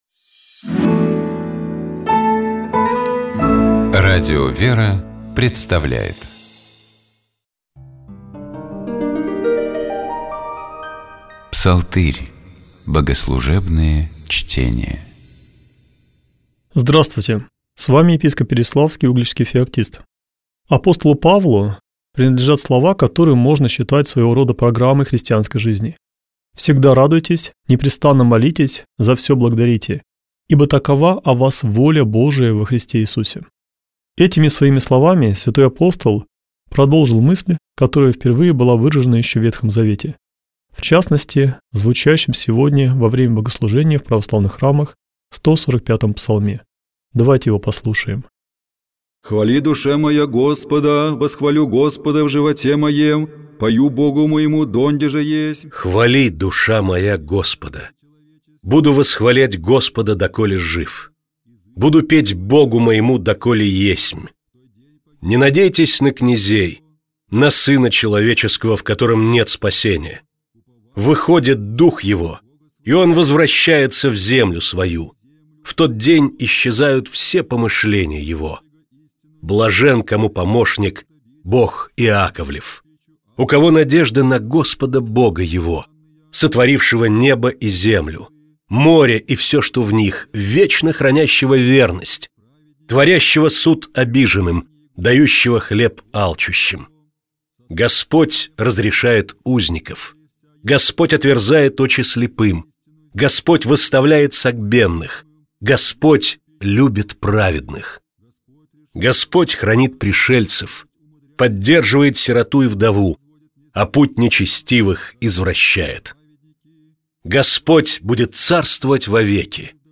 Псалом 145. Богослужебные чтения